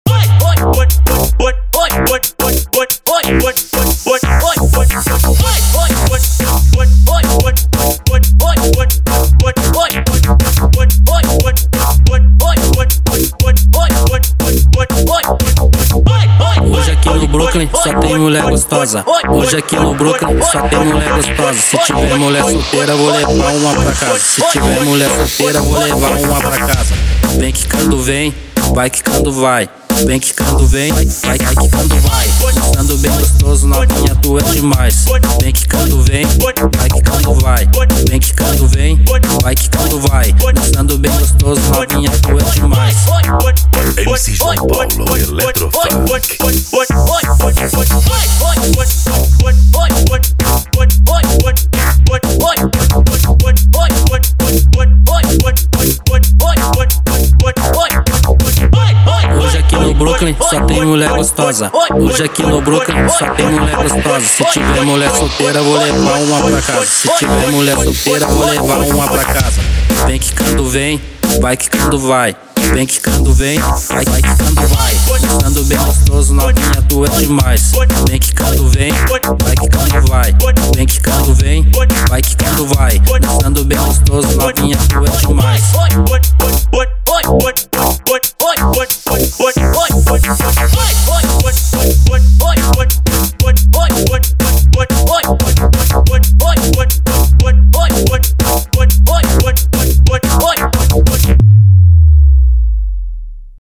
eletrofunk pancadao